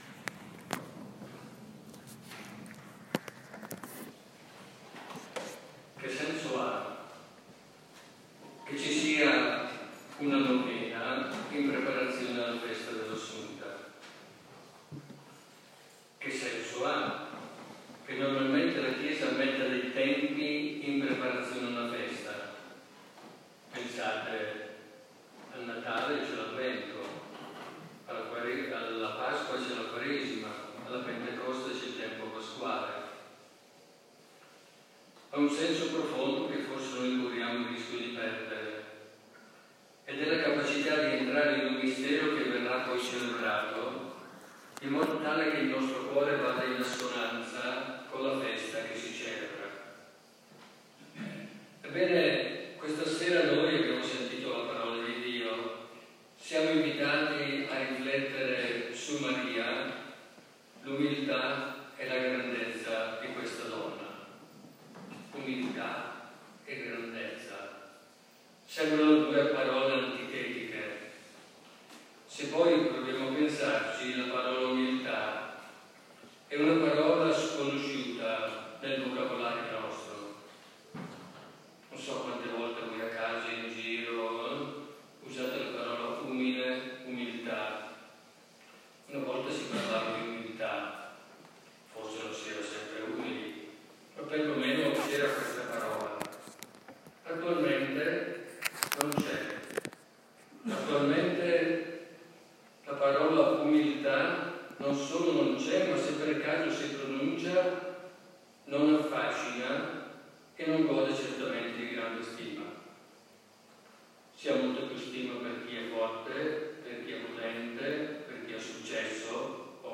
Omelia-8-agosto-2022.mp3